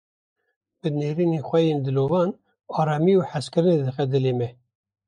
/ɑːɾɑːˈmiː/